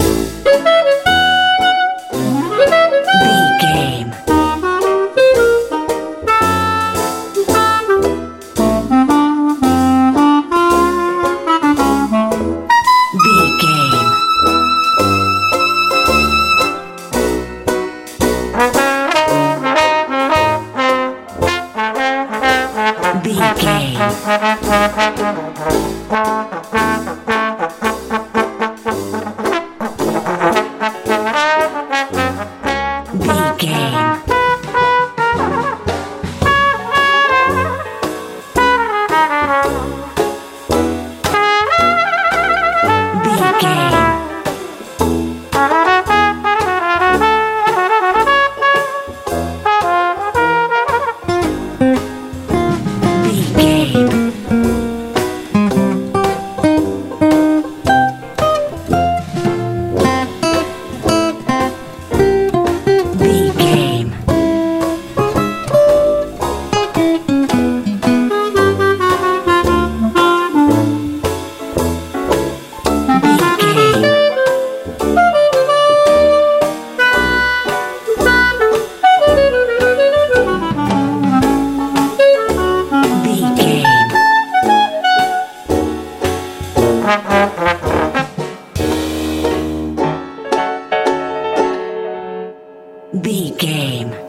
dixieland jazz feel
Ionian/Major
bright
horns
banjo
bass guitar
drums
fun
happy